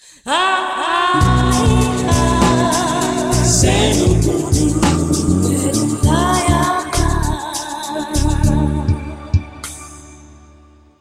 Indicatiu curt de l'emissora